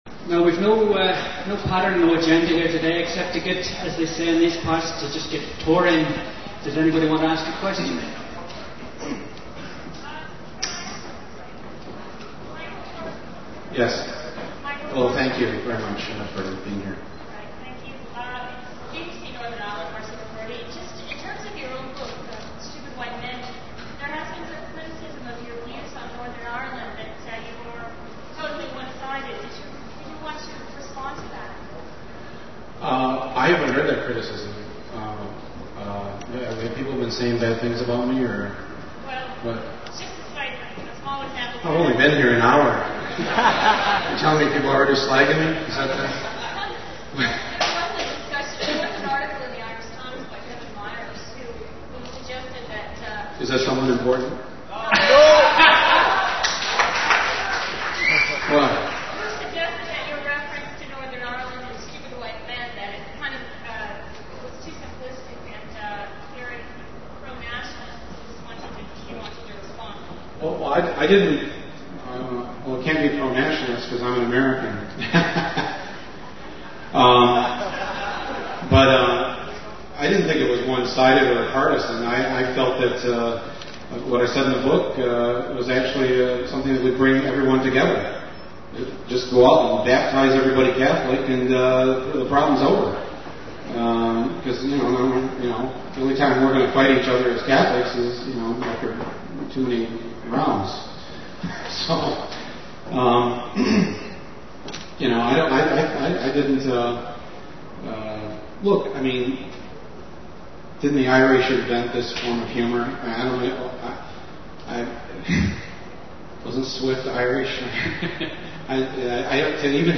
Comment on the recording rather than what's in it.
Location Recorded: Belfast, N. IRELAND